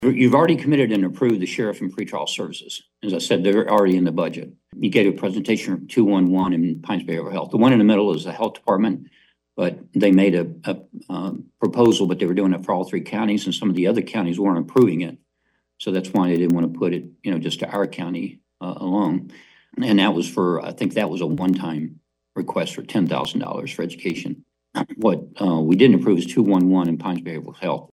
Branch County Administrator Bud Norman gave a recap for Commissioners during their August 1 work session.